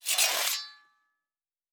Fantasy Interface Sounds
Blacksmith 10.wav